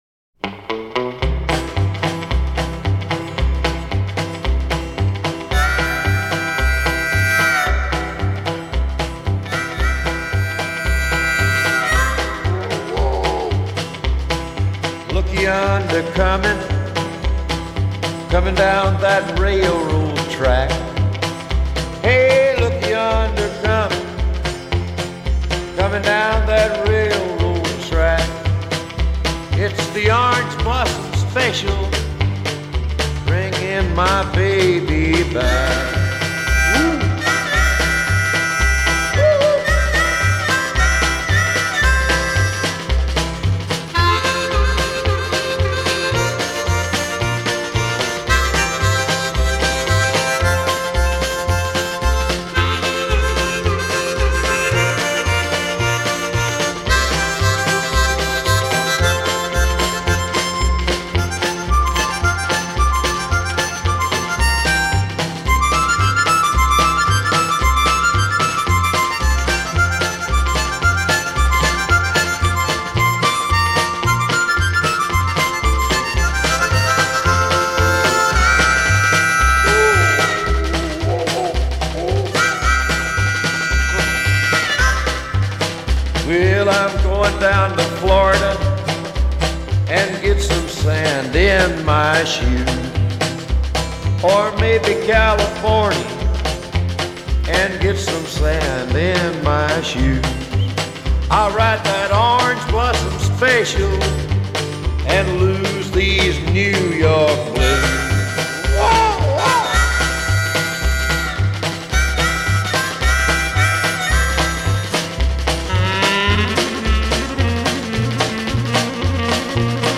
country کانتری